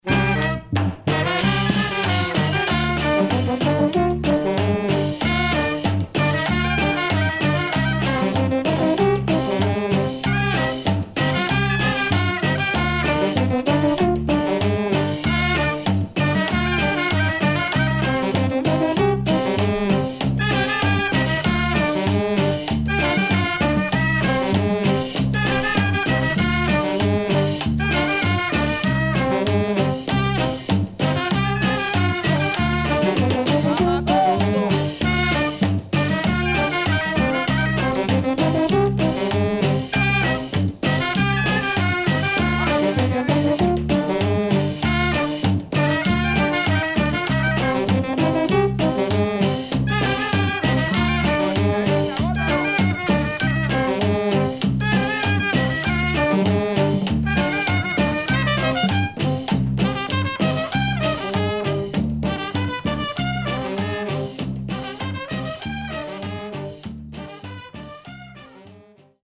パーカッション主体にアコーディオンのメロディーライン。そしてベースラインはレゲエにも似た所があるというクンビア。